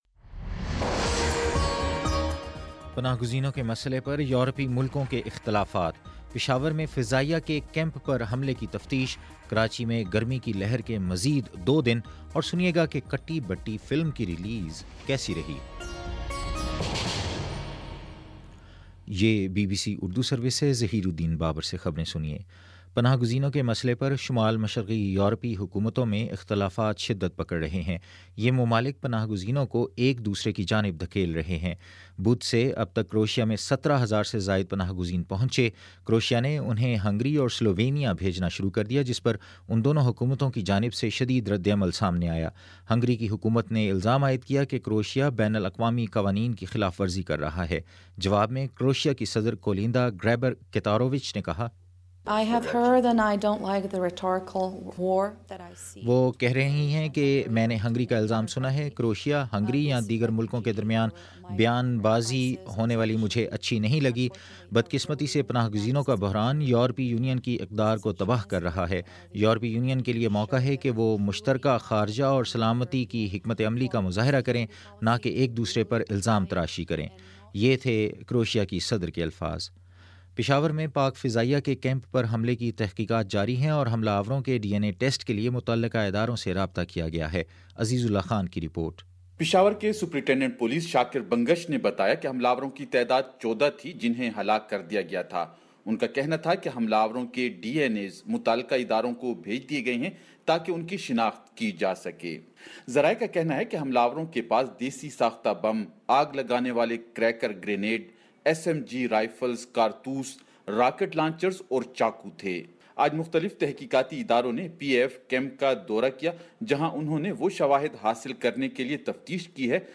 ستمبر19 : شام چھ بجے کا نیوز بُلیٹن